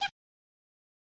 Unused voice clip from Wrecking Crew '98
WC98_Unused_Voice_2.oga.mp3